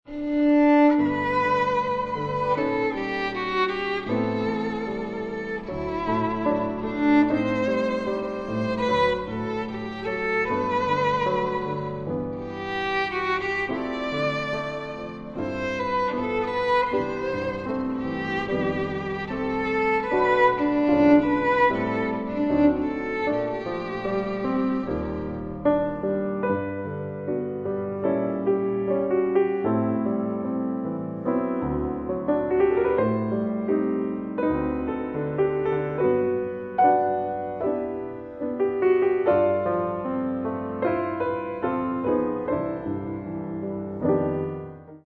インストゥルメンタル
●ピアノ演奏
※歌は収録されていません。
Violin